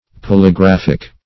Search Result for " polygraphic" : The Collaborative International Dictionary of English v.0.48: Polygraphic \Pol`y*graph"ic\, Polygraphical \Pol`y*graph"ic*al\, a. [Cf. F. polygraphique.]